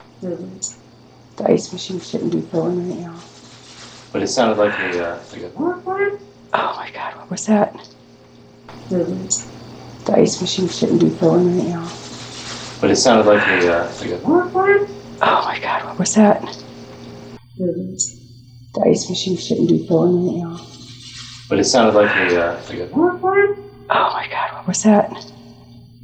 Basement
Breath
breath.wav